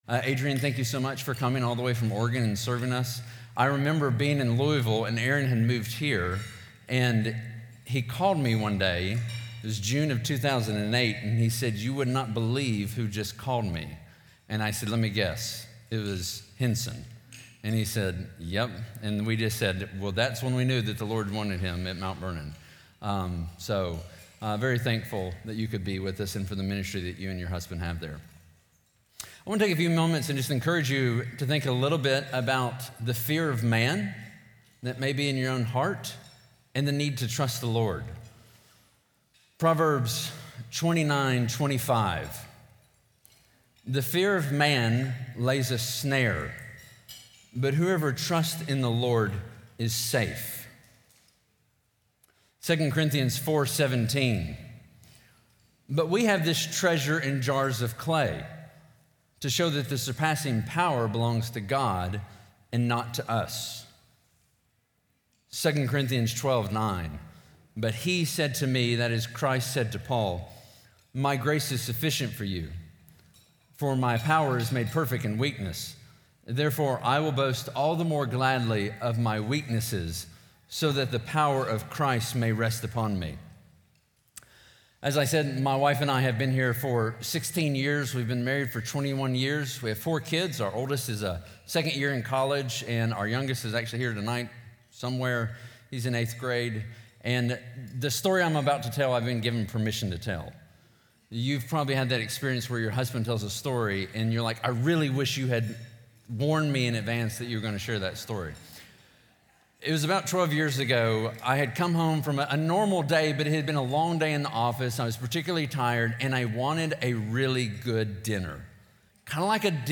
Audio recorded at Feed My Sheep for Pastors Wives Conference 2024.